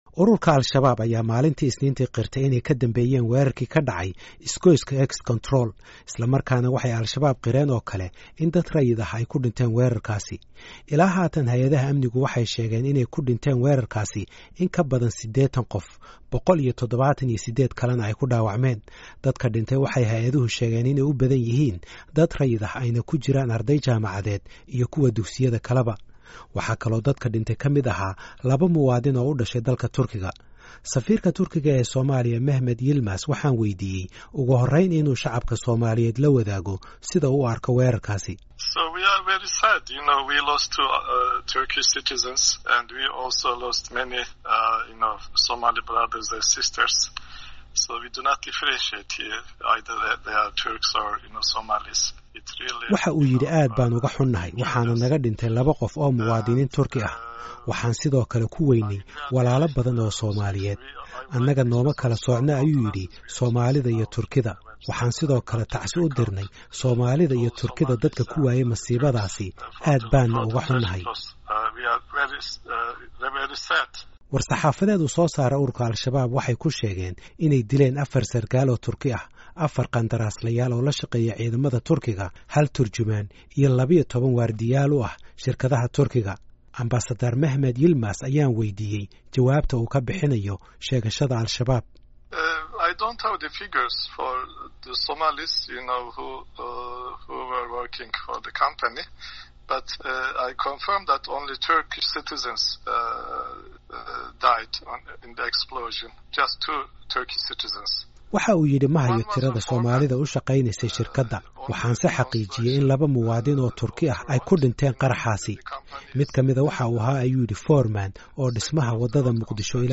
Waraysi khaas ah oo uu siiyey idaacadda VOA ayuu ku sheegay oo kale in ajande aan ka ahayn mid bani’aadminnimo iyo in Soomaaliya ku istagao lugaheeda aysan Turkigu ka lahayn dalka.